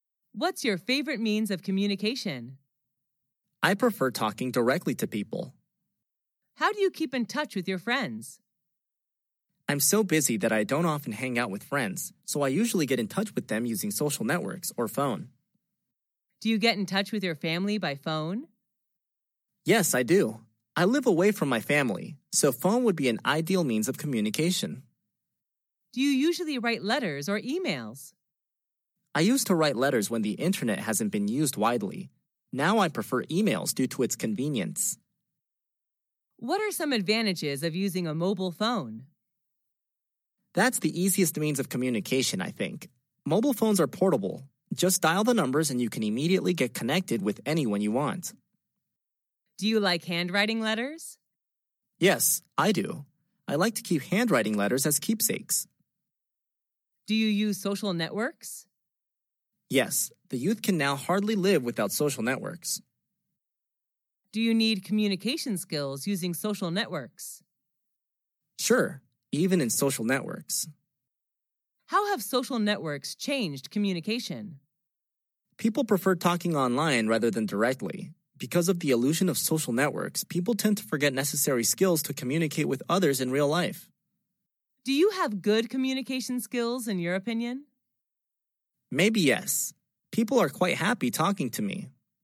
Sách nói | QA-56